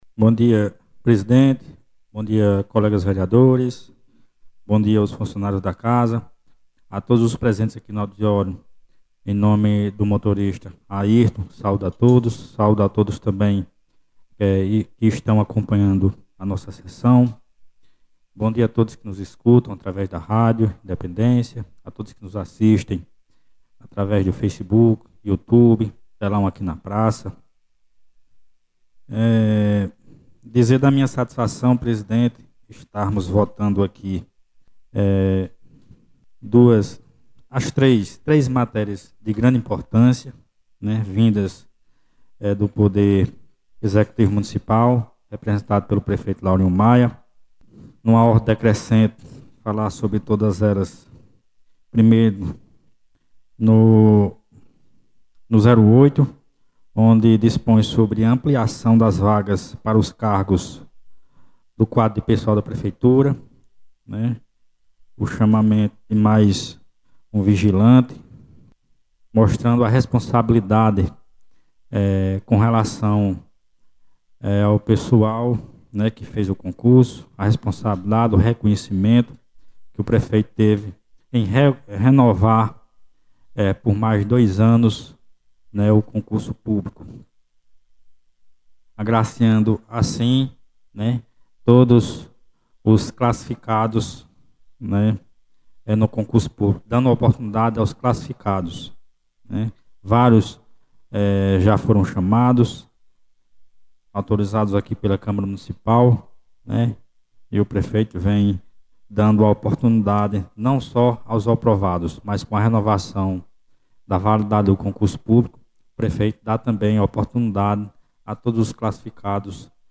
Durante a Sessão Extraordinária da Câmara Municipal de Catolé do Rocha, o vereador Daniel Cavalcante fez um pronunciamento marcado pelo reconhecimento ao trabalho dos motoristas do município, elogios à gestão do prefeito Laurinho Maia e pela defesa da responsabilidade fiscal que tem permitido a ampliação de direitos dos servidores municipais.
Vereador-Daniel-Cavalcante.mp3